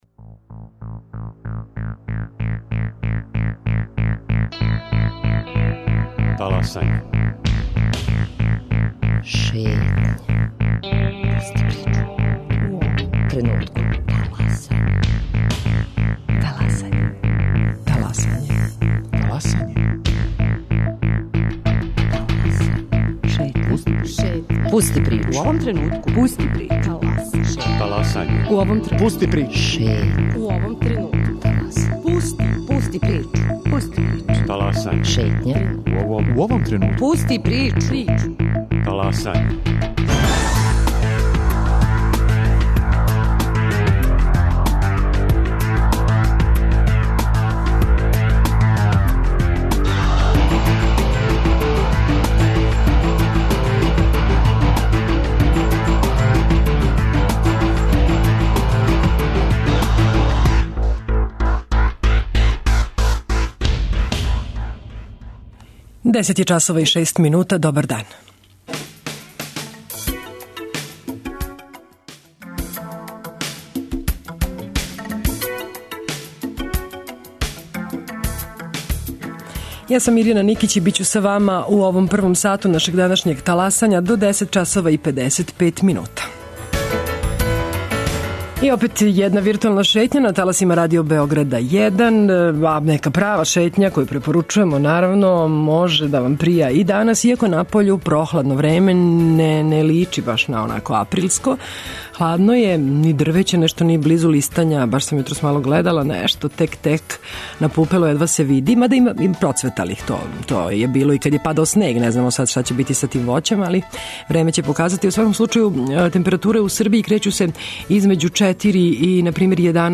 У Зоолошком врту биће и репортер Радио Београда 1, а улаз за посетиоце је бесплатан.